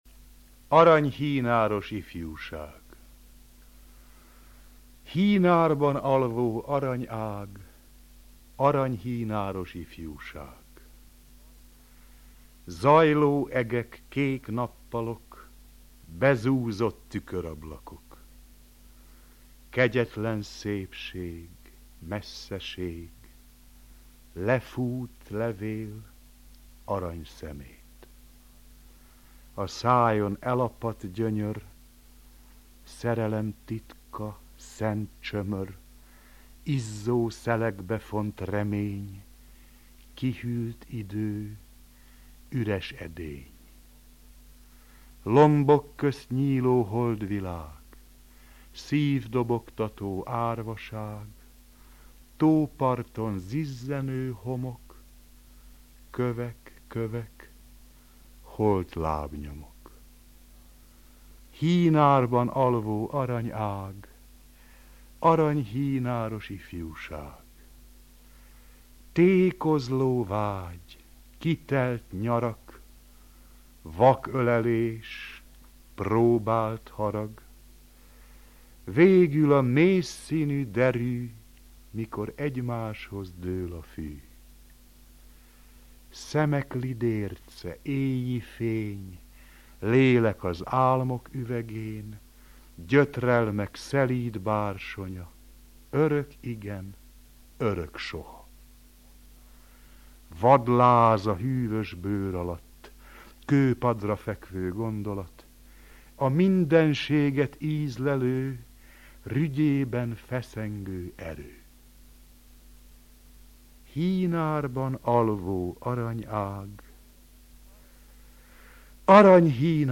A CSEHSZLOVÁK RÁDIÓ MAGYAR ADÁSÁBAN MAGYARORSZÁGI SZÍNÉSZEK ELŐADÁSÁBAN SZÁMOS VERSE VOLT HALLHATÓ. AZ ARANYHÍNÁROS IFJÚSÁG C. KÖLTEMÉNYÉT EGY KÖZISMERT SZÍNMŰVÉSZ TOLMÁCSOLTA.
denes-latinovits-hinarban-alvo-vers.mp3